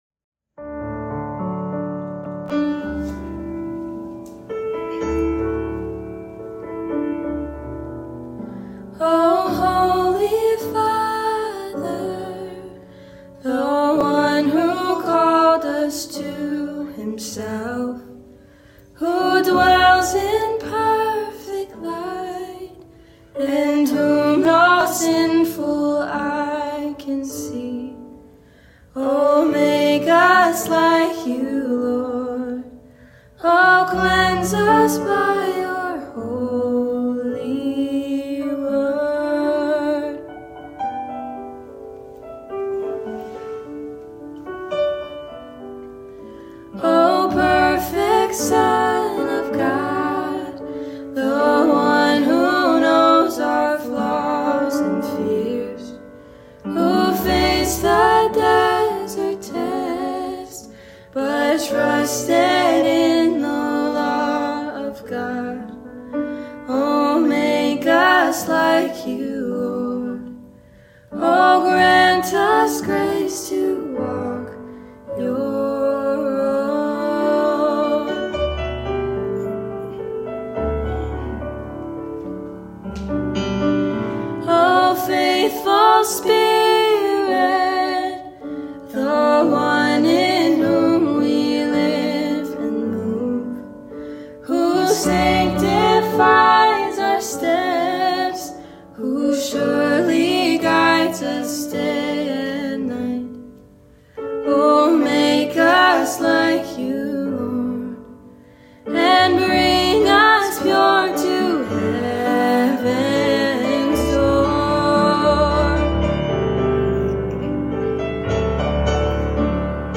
Morning Worship Services – 9:00am & 10:45am